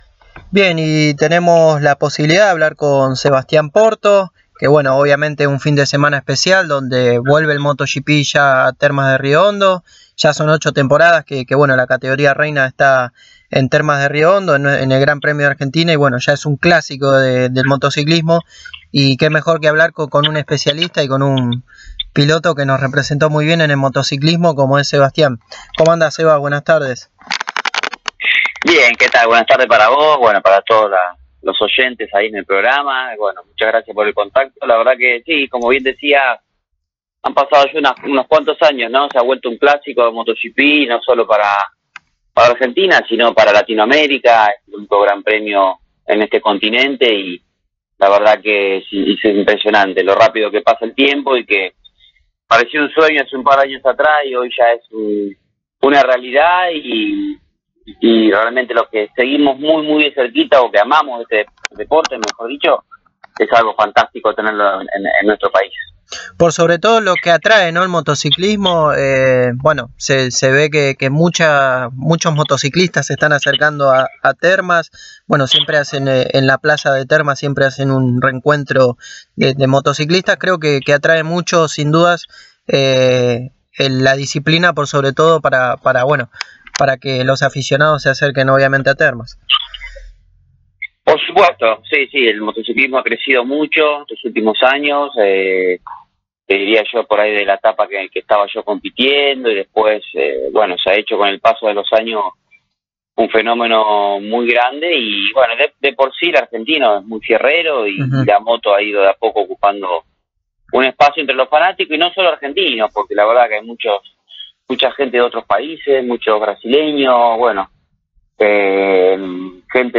El máximo representante que tuvo la argentina en el motociclismo mundial, Sebastián Porto, pasó por los micrófonos de Pole Position y nos brindó un análisis de lo que será esta fecha del Moto GP en Termas de Río Hondo. Habló de las nuevas actualizaciones en las motos, del formato sprint y del momento que está viviendo Marc Márquez en el mundial.